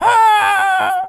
monkey_hurt_scream_07.wav